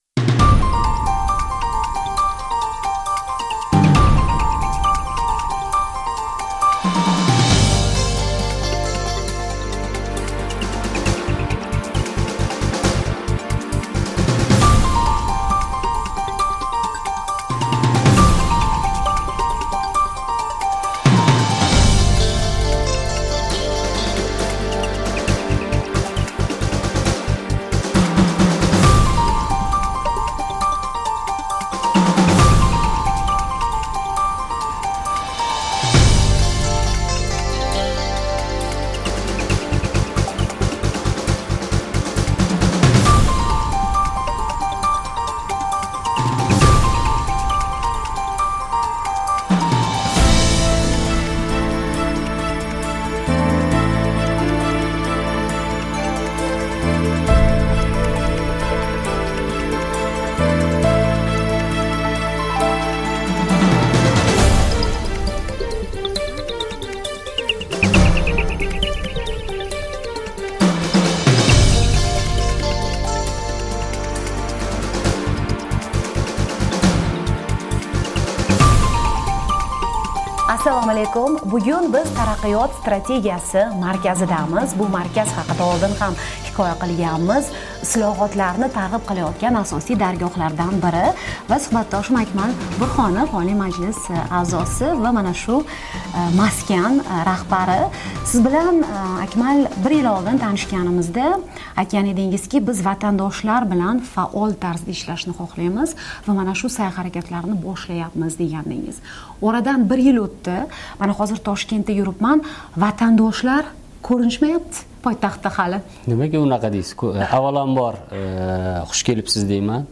"Amerika Ovozi" yaqinda Toshkentda "Taraqqiyot strategiyasi" markazi rahbari, deputat Akmal Burhanov bilan aynan shu masalada gaplashdi.